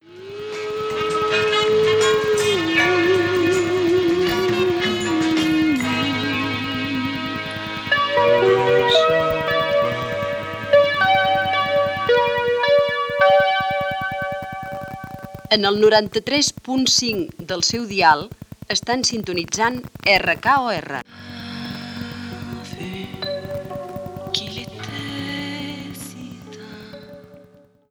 Indicatiu de l'emissora i música. Gènere radiofònic Musical